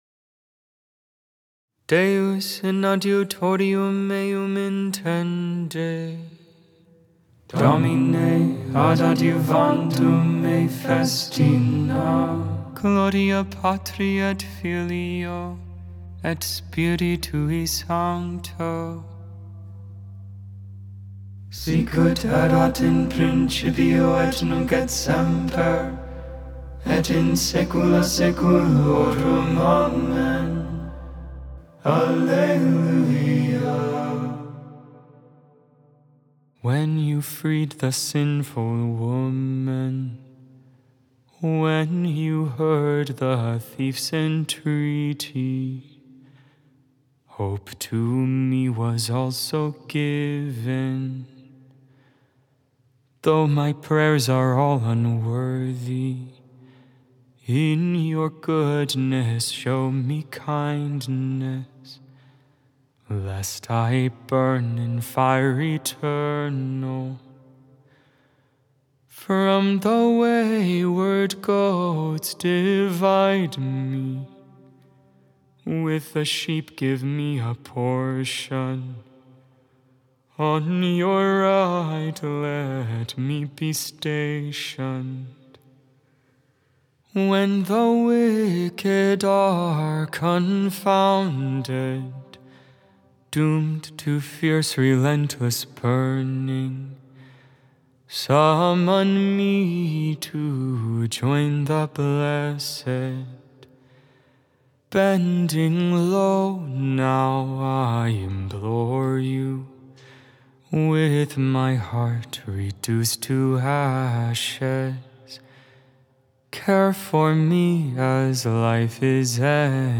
11.25.24 Vespers, Monday Evening Prayer